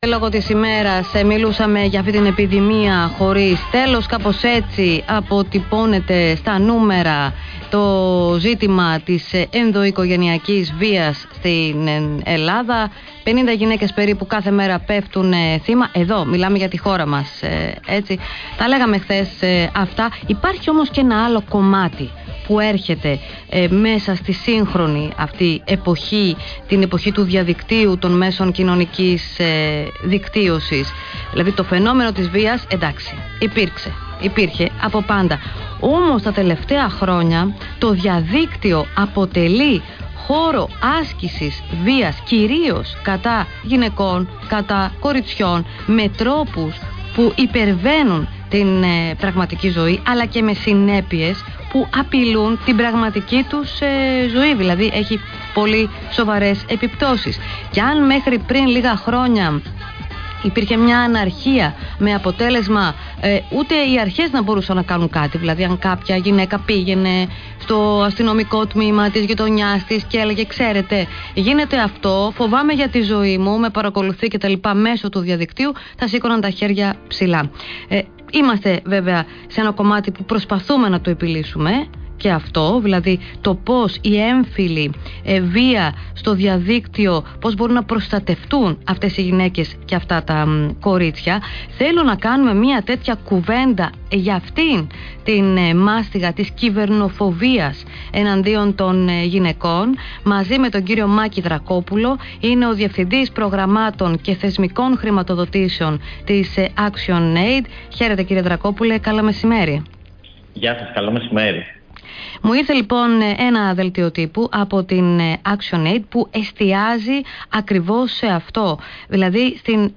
Συνέντευξη στο Πρώτο Πρόγραμμα της ΕΡΤ: Έμφυλη βία στο διαδίκτυο